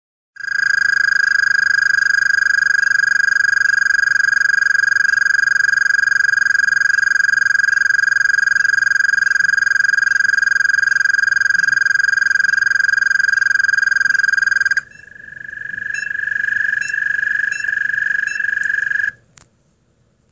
The males will begin calling a long trill that may last as much as 30 seconds.
AmToad.m4a